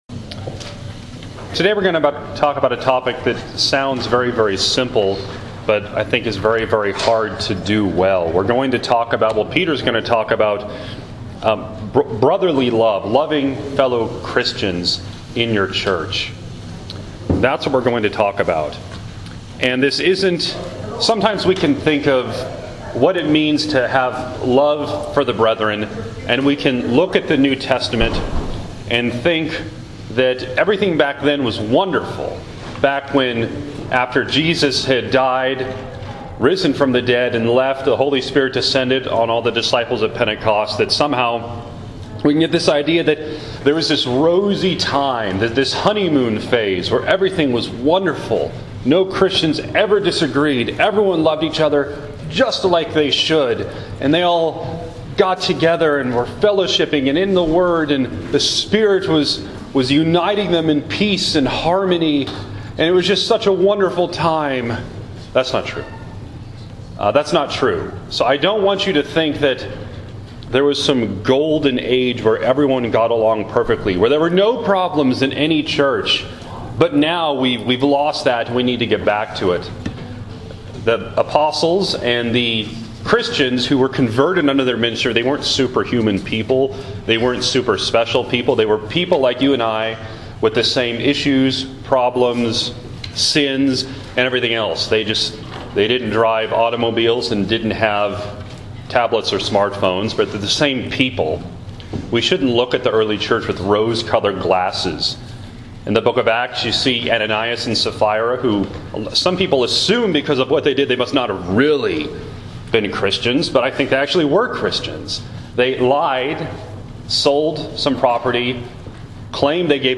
This past Sunday School, I discussed the first bit of Peter’s command from 1 Peter 1:22 – 2:3.